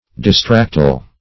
Distractile \Dis*tract"ile\, a.